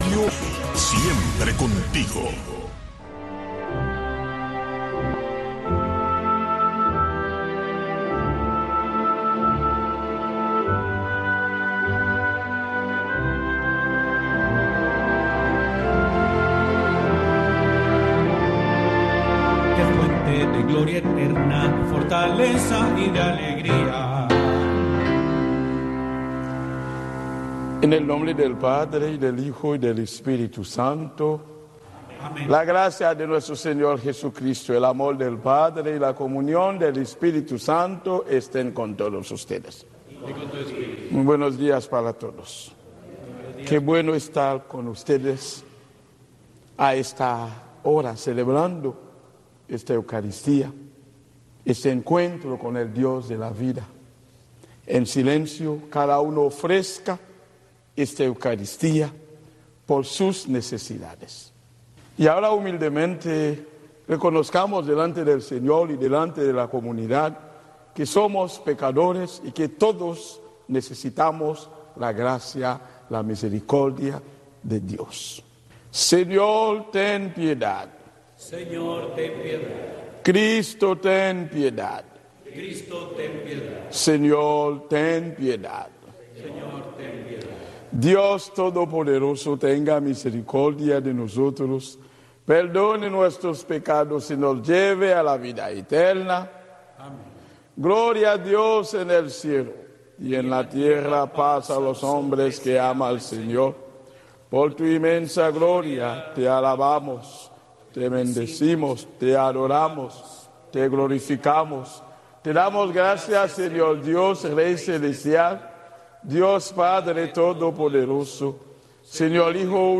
La misa dominical transmitida para Cuba desde el Santuario Nacional de Nuestra Señor de la Caridad, un templo católico de la Arquidiócesis de Miami dedicado a la Patrona de Cuba.